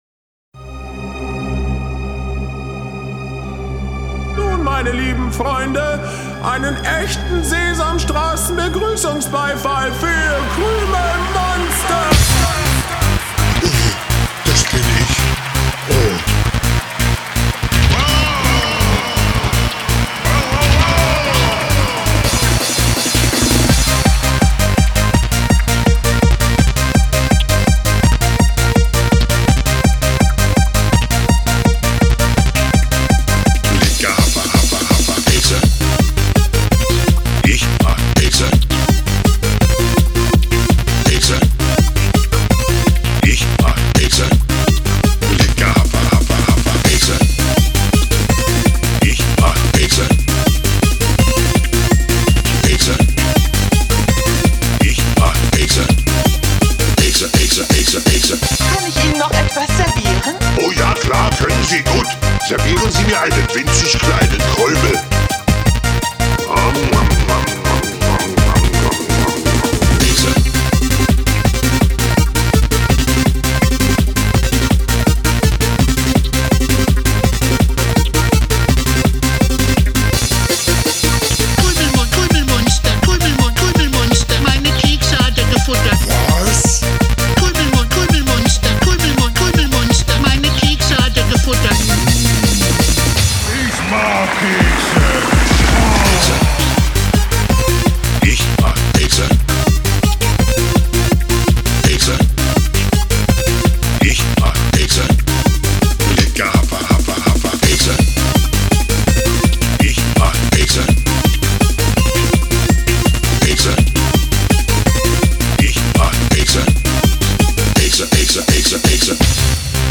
В финале - апплодисменты - значит он её съел)